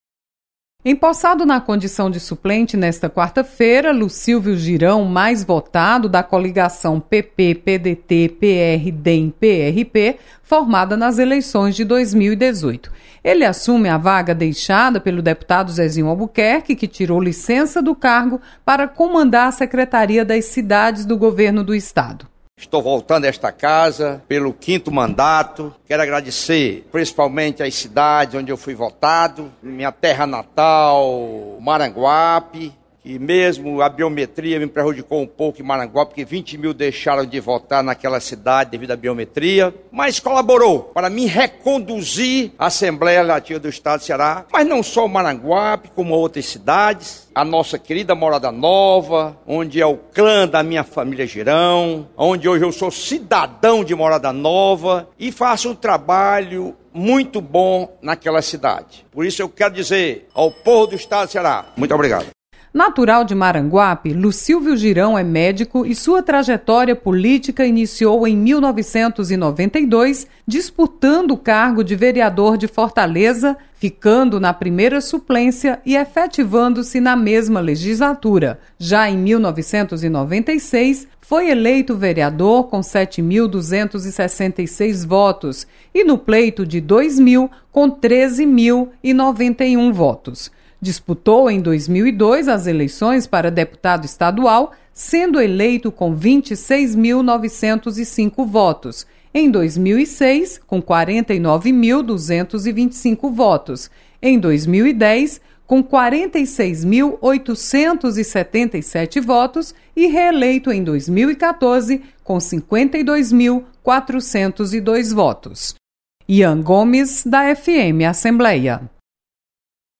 Lucílvio Girão assume vaga deixada pelo deputado licenciado, Zezinho Albuquerque. Repórter